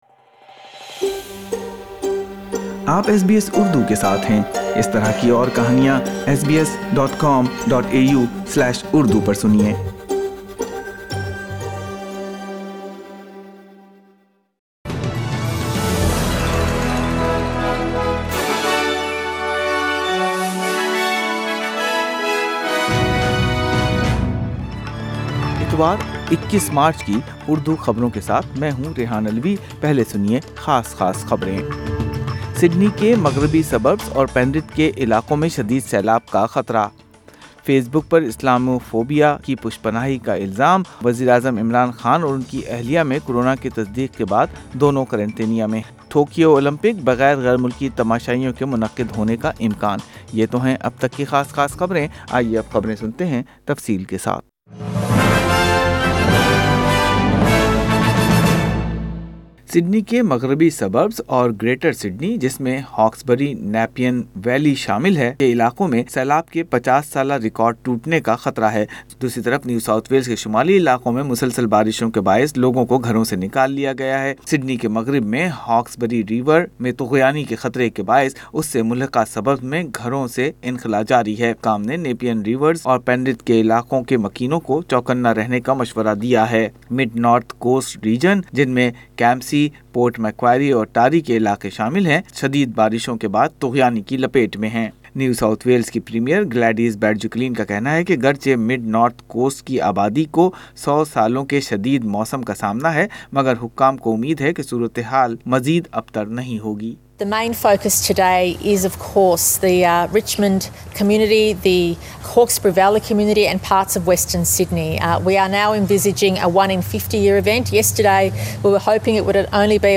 In this bulletin, Parts of New South Wales experienced the worst flooding in decades, Facebook accused of not taking action against Islamophobia, PM Imran Khan tested Covid positive